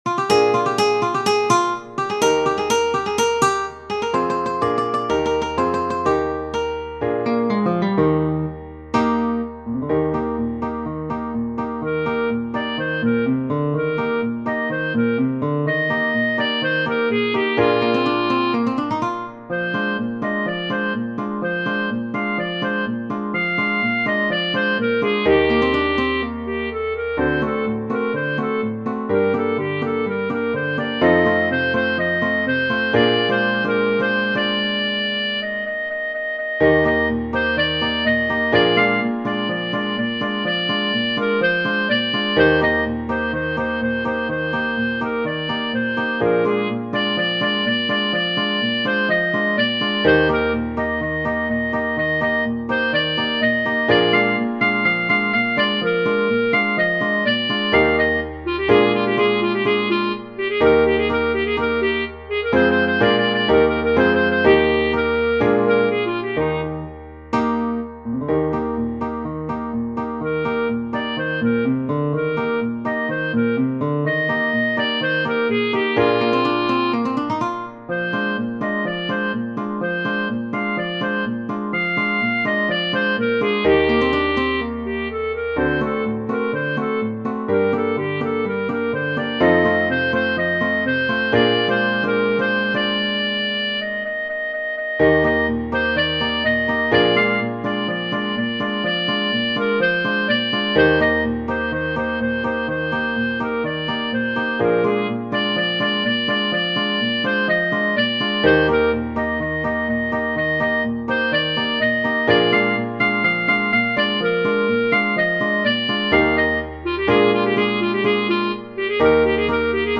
un brano della canzone napoletana